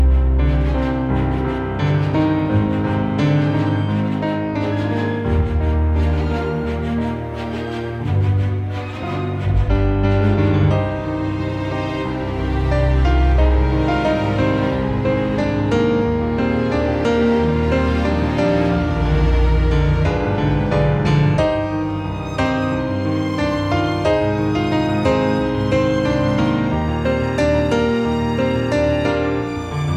Жанр: Поп музыка / Соундтрэки